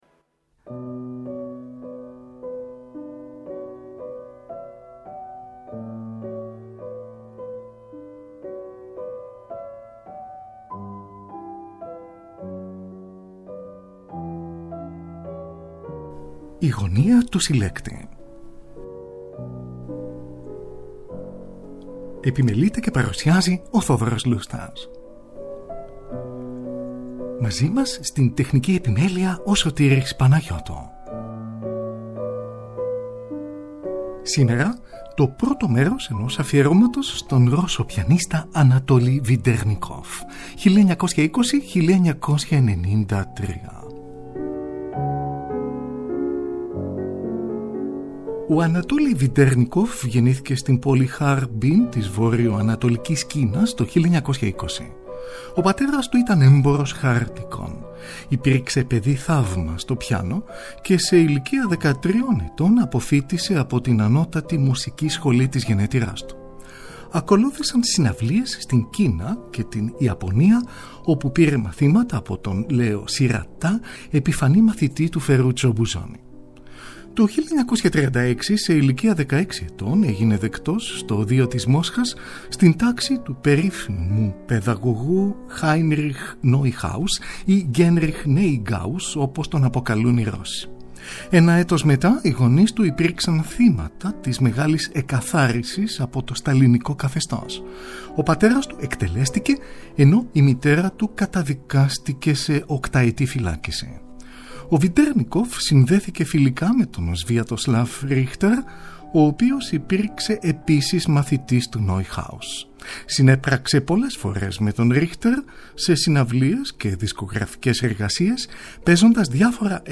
César Franck : Πρελούδιο, Φούγκα και Παραλλαγή, έργο 18, για Εκκλησιαστικό Όργανο ή για Πιάνο και Αρμόνιο , σε μεταγραφή του ίδιου του Vedernikov , για σόλο πιάνο.